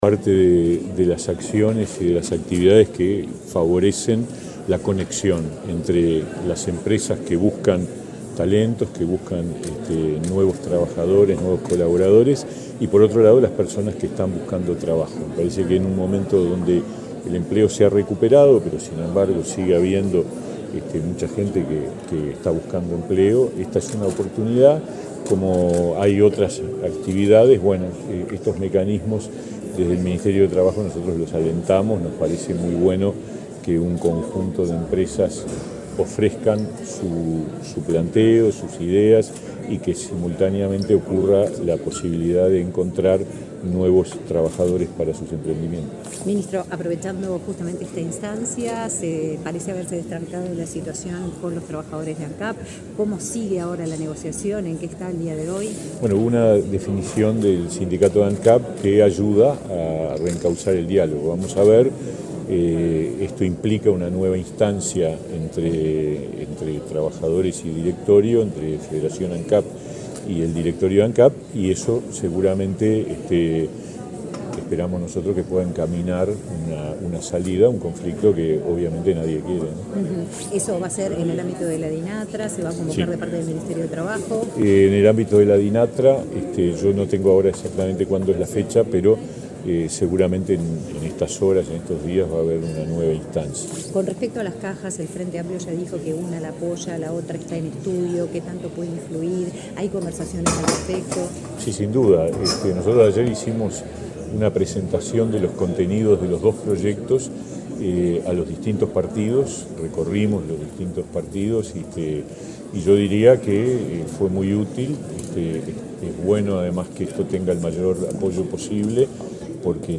Declaraciones del ministro de Trabajo, Pablo Mieres
Este miércoles 27 en la Torre de las Telecomunicaciones, el ministro de Trabajo, Pablo Mieres, dialogó con la prensa, luego de participar en la